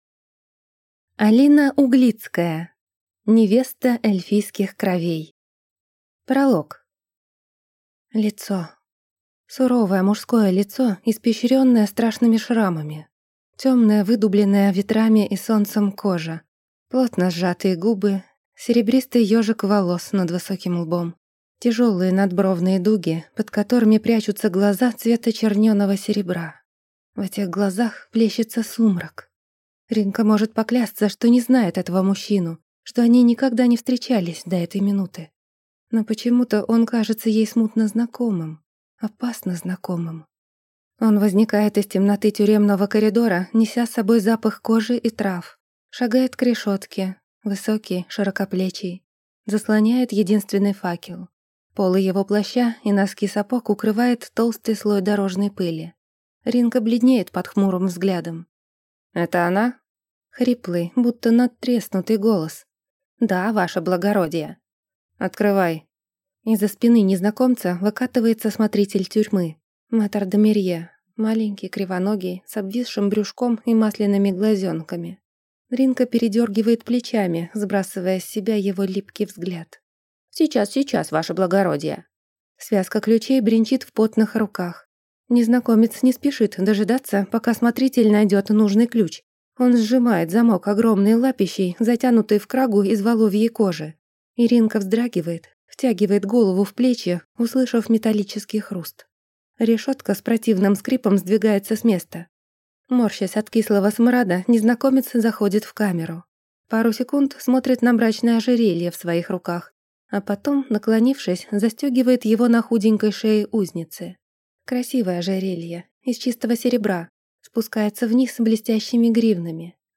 Аудиокнига Невеста эльфийских кровей | Библиотека аудиокниг